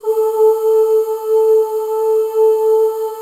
A3 FEM OOS.wav